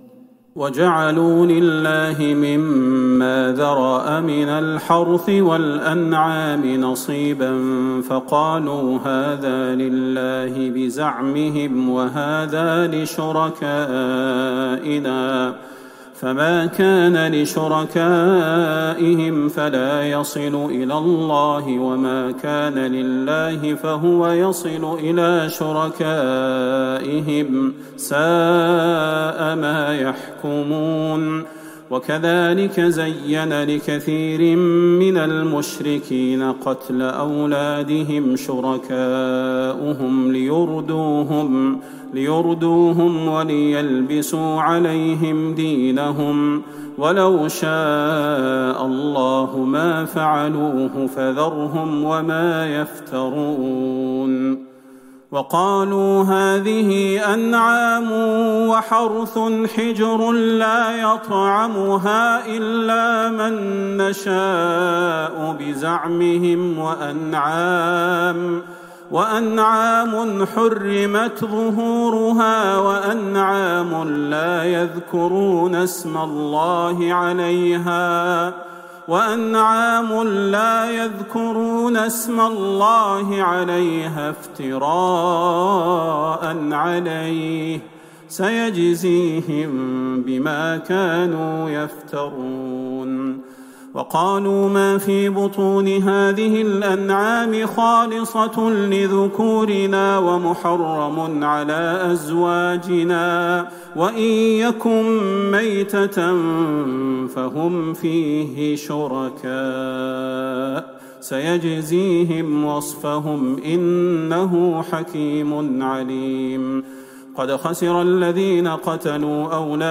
ليلة ١٠ رمضان ١٤٤١هـ من سورة الأنعام { ١٣٦-١٦٥ } > تراويح الحرم النبوي عام 1441 🕌 > التراويح - تلاوات الحرمين